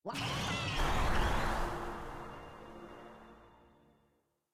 sfx-pm-level-unlock-5.ogg